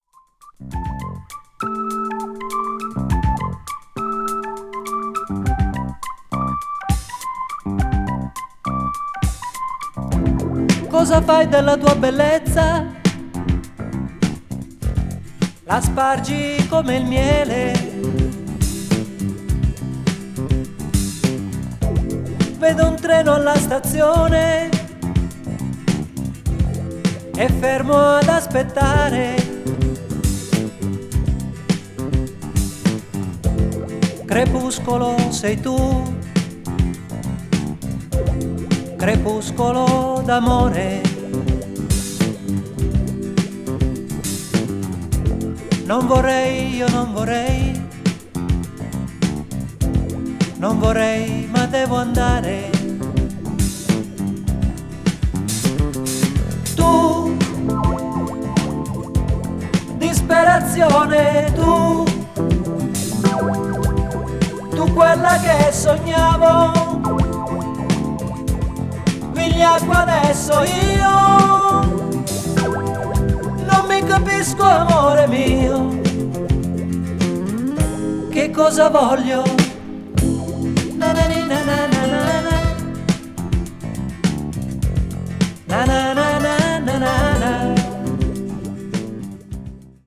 a.o.r.   italian pop   mellow groove   progressive rock